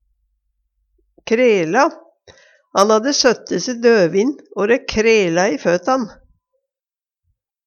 krela - Numedalsmål (en-US)